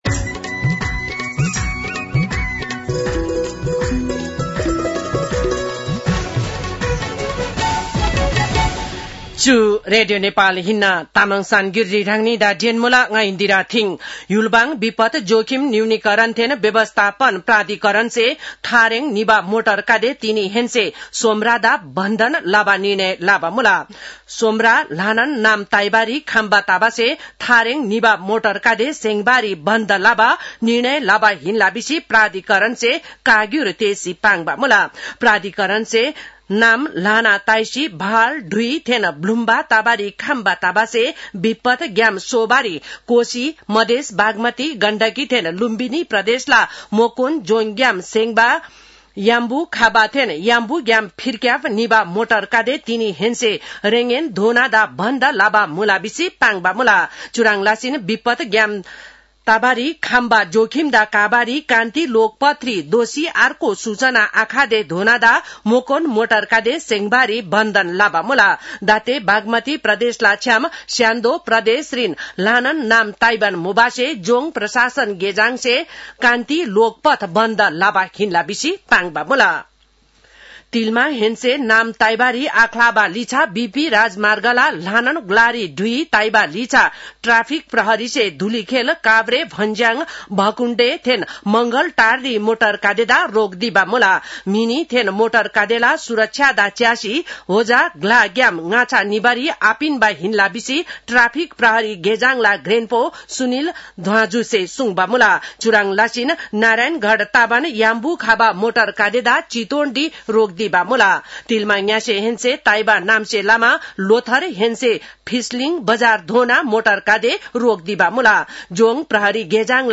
तामाङ भाषाको समाचार : १८ असोज , २०८२